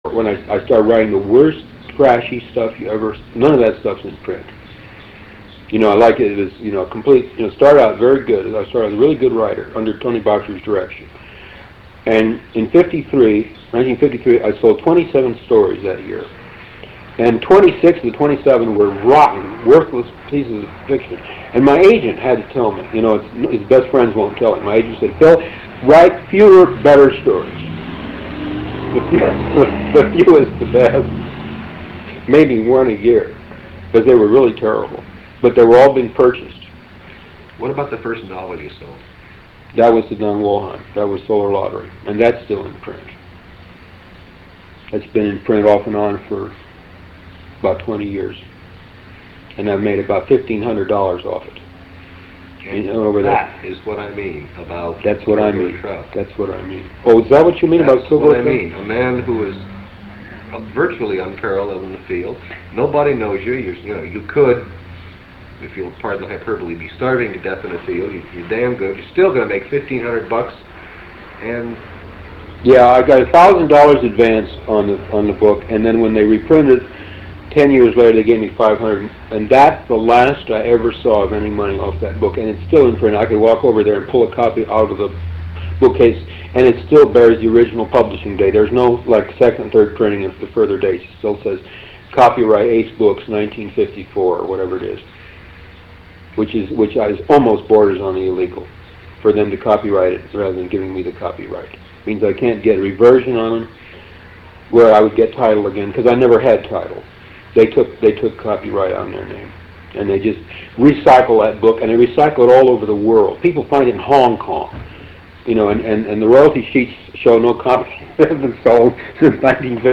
Interview with Philip K Dick 3